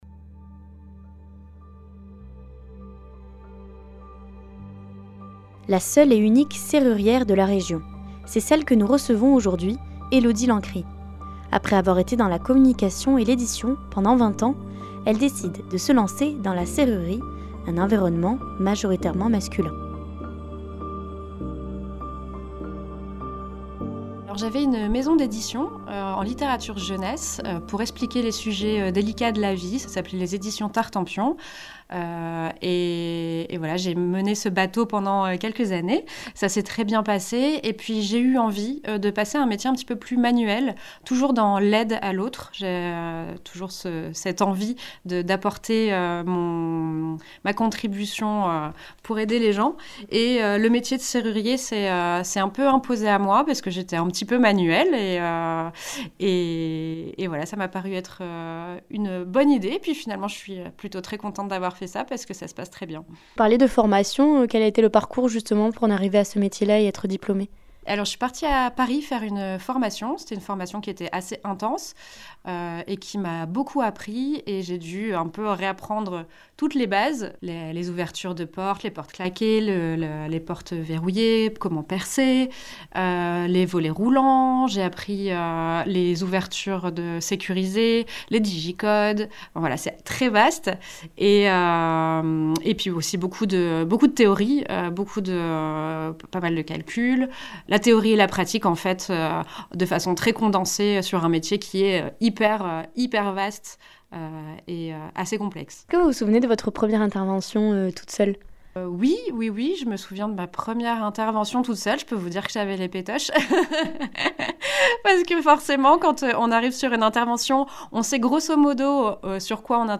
Elle nous partage son parcours et son quotidien dans ce reportage.